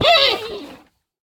Minecraft Version Minecraft Version snapshot Latest Release | Latest Snapshot snapshot / assets / minecraft / sounds / mob / panda / death2.ogg Compare With Compare With Latest Release | Latest Snapshot